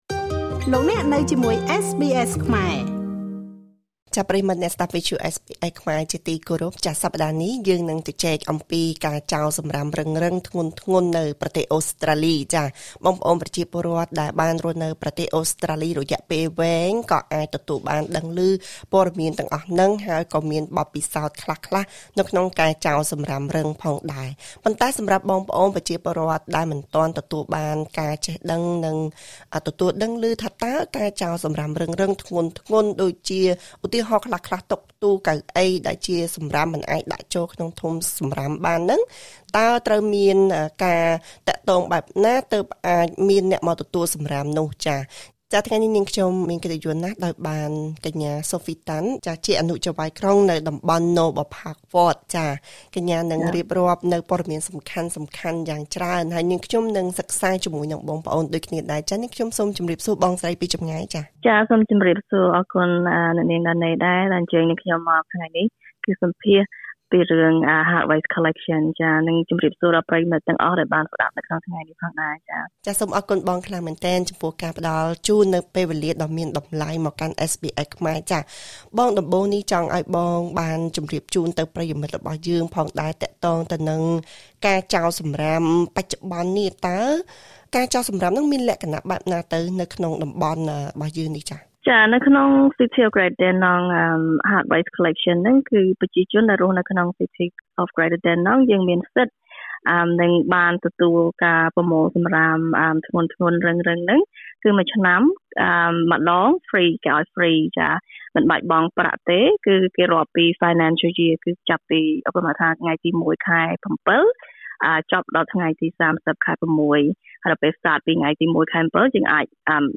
ប្រជាពលរដ្ឋនៅក្នុងក្រុងក្រេដថឺដាឌីណង់ (Greater Dandenong) នៃរដ្ឋវិចថូរៀ មានសិទ្ធទទួលបានការប្រមូលសំរាមធំៗ ធ្ងន់ៗ ដោយឥតគិតថ្លៃមួយឆ្នាំម្តង ប៉ុន្តែដើម្បីអាចទទួលបានសេវាកម្មនេះ គ្រប់គ្នាត្រូវធ្វើតាមសេចក្តីណែនាំឲ្យបានត្រឹមត្រូវ។ សូមស្តាប់បទសម្ភាសន៍ជាមួយកញ្ញា តាន់ សូហ្វី ចៅហ្វាយក្រុងរងនៃ ក្រុងក្រេដថឺដាឌីណង់ និងជាក្រុមប្រឹក្សាសង្កាត់នៅតំបន់ណូប៊លផាកវ័ត (Noble Park Ward)។